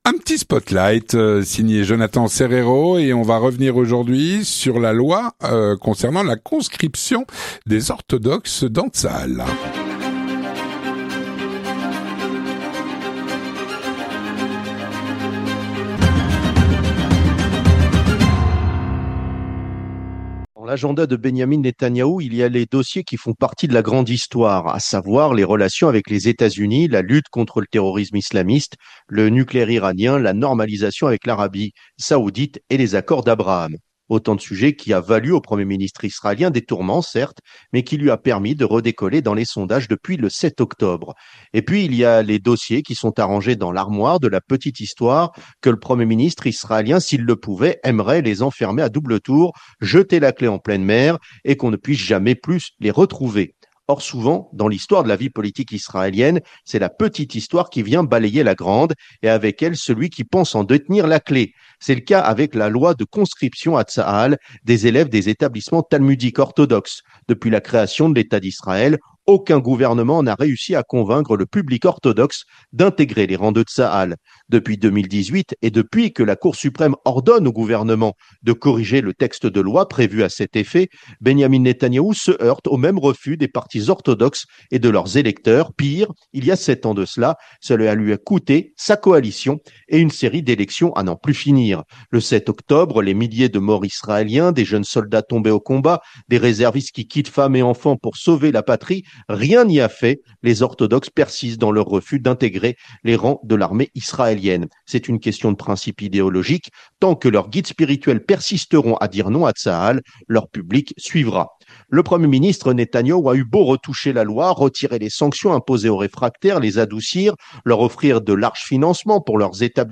Une chronique